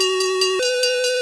fires.wav